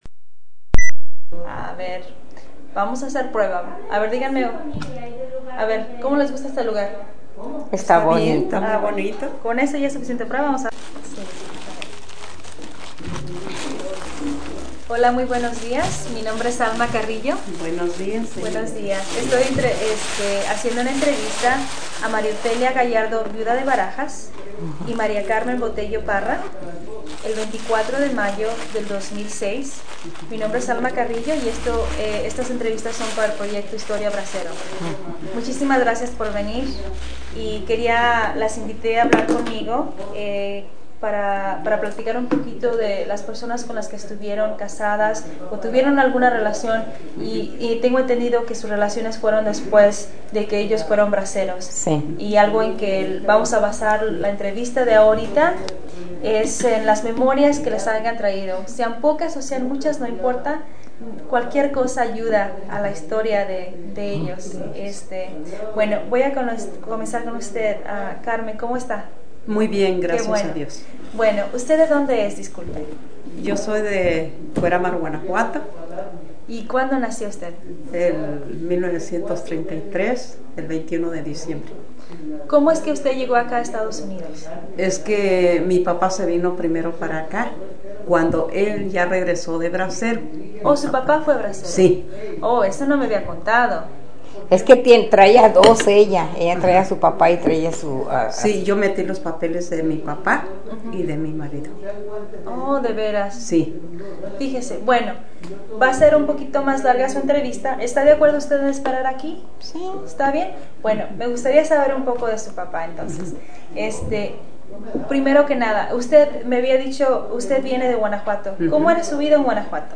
Location Heber, CA Original Format Mini disc